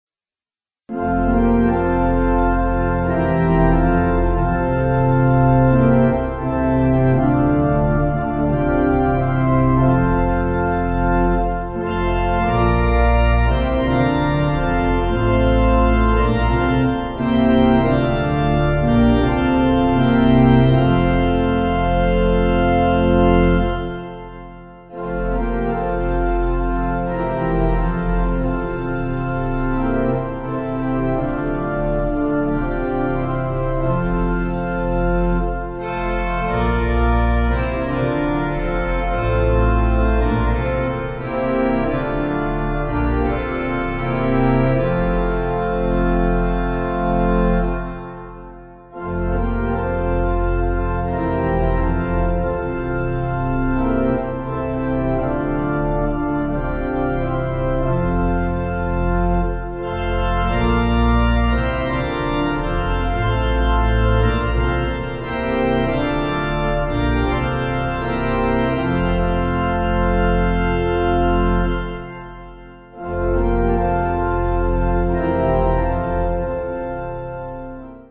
Organ
Slower